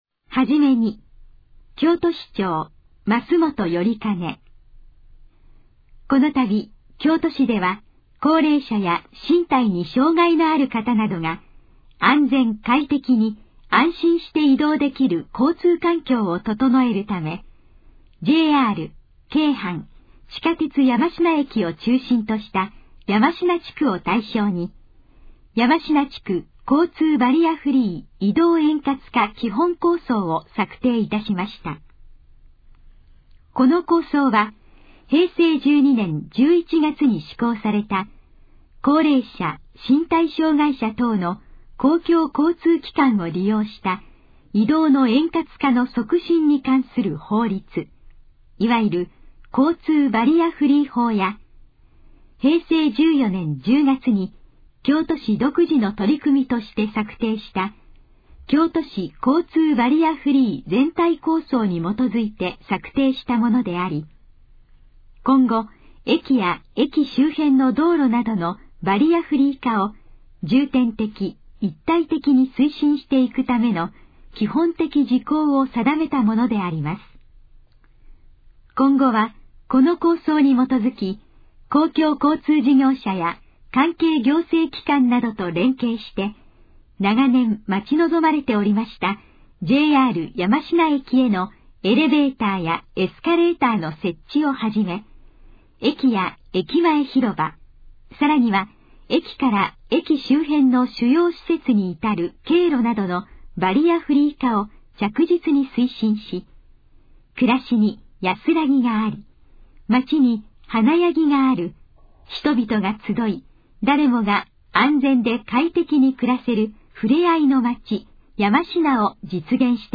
このページの要約を音声で読み上げます。
ナレーション再生 約302KB